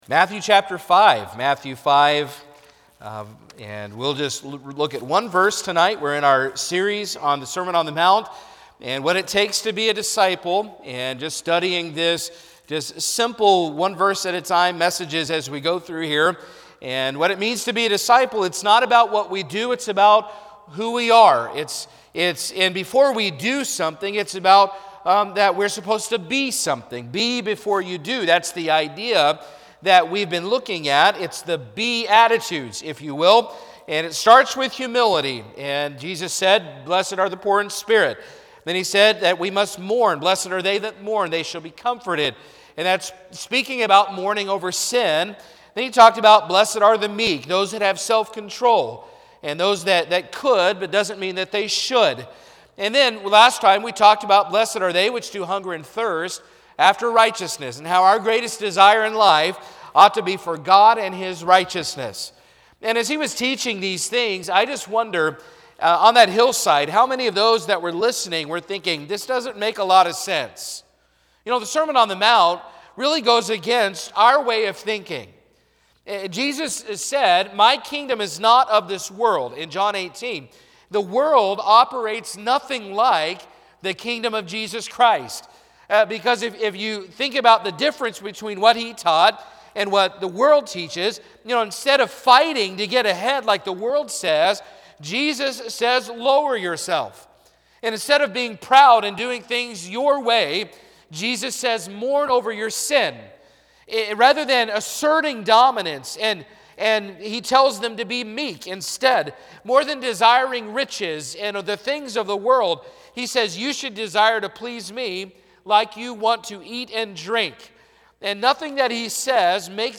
Eastside Baptist Church Sermon Podcast